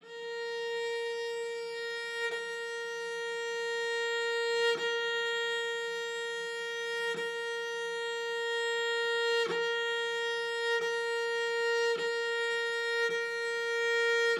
Long Sustain f A#4.wav